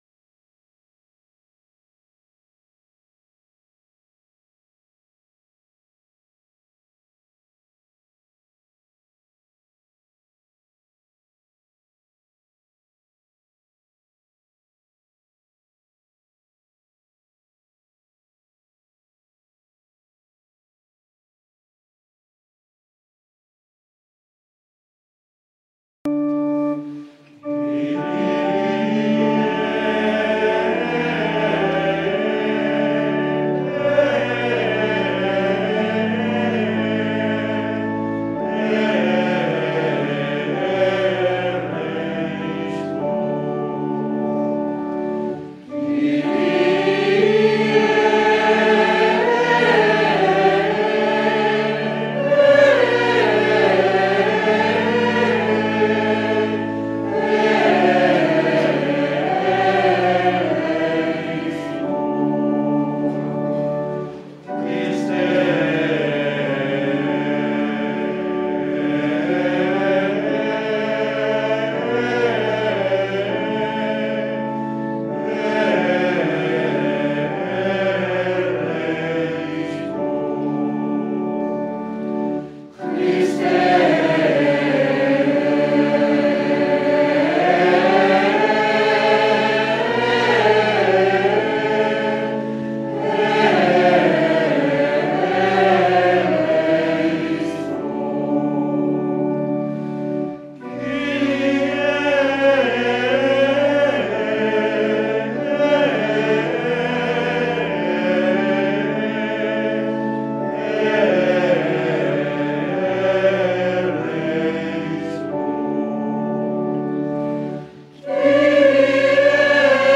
0.그레고리안 천사 미사곡(4곡연속).mp3
분당 야탑동성당 체칠리아성가대 (2018년 성탄미사곡)
Mass of the Angels, Gregorian Chant